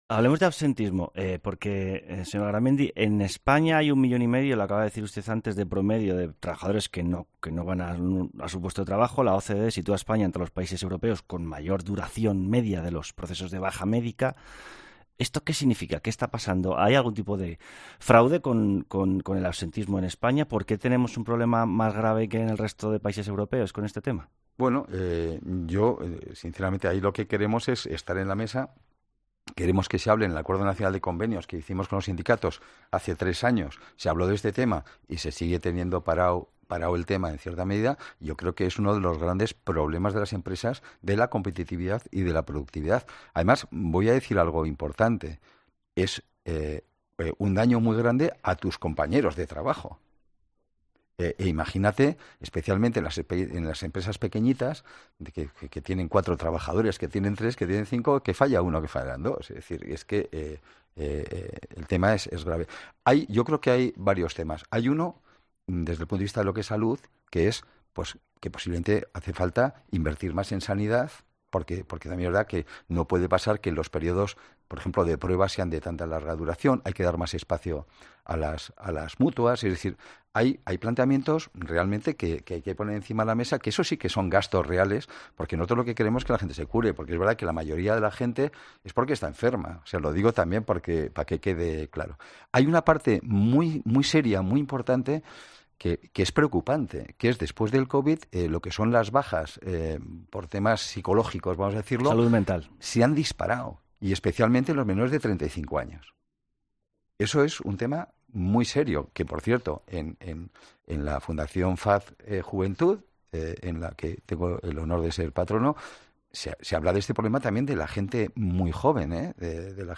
El presidente de la CEOE analiza con Jorge Bustos el problema del absentismo laborales, que lastra la productividad y que, asegura, requiere un debate serio y sin "ocurrencias"
El presidente de la CEOE, Antonio Garamendi, habla sobre el absentismo laboral en España